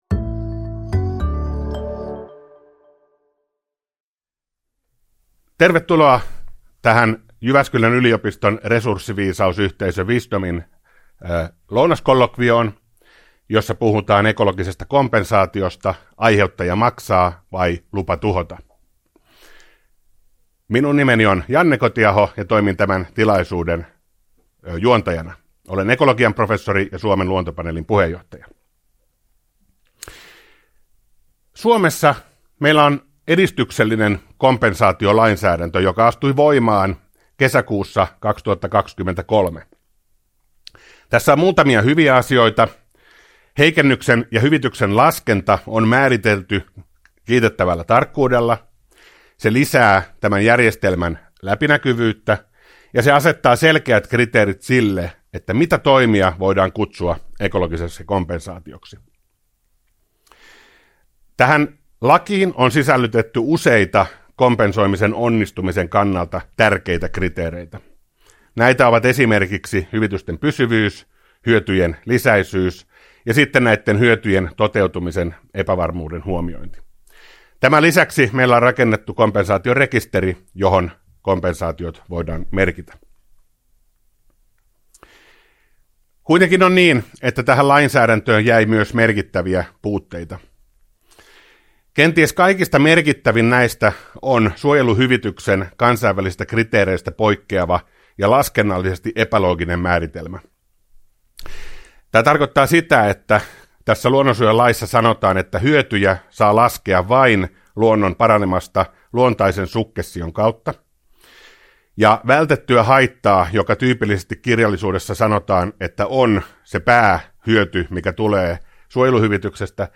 Tallenne JYU.Wisdomin lounaskollokviosta 29.5.2024.